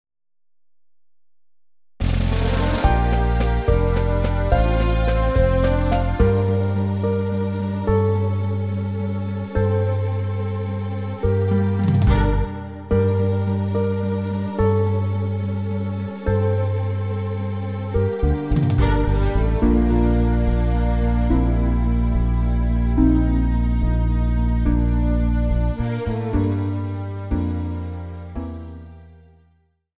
Recueil pour Clarinette